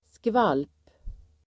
Uttal: [skval:p]